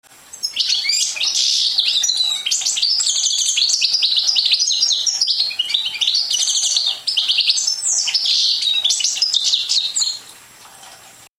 Both sexes sing, although the male's song is fuller.
Song
Song is most typically performed from the end of February to mid-July and again from late September to December; it is described as pleasant, fluid, and canary-like.
rEuropeanGoldfinch.mp3